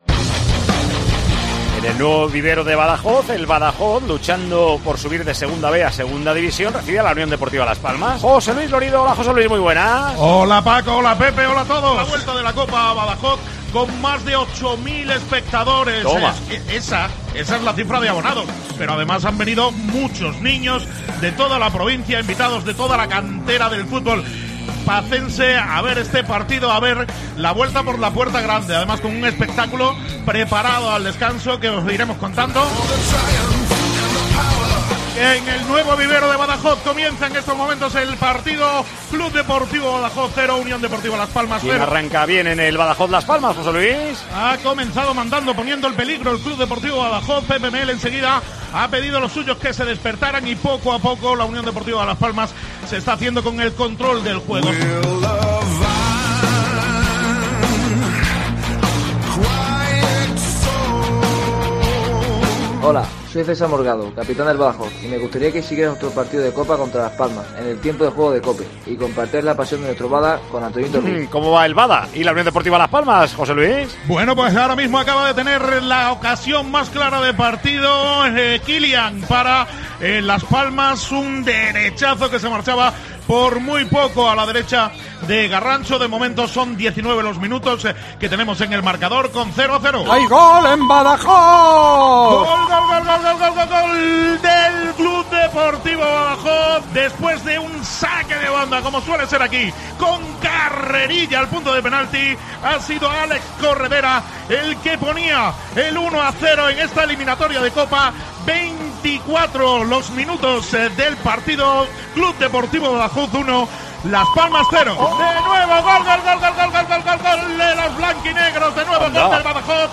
Los sonidos de la clasificación del CD Badajoz
El partido Badajoz - Las Palmas, cola clasificación de los pacenses para dieciseisavos de Final, dejo huella sonora en la progrmación de Tiempo de Juego.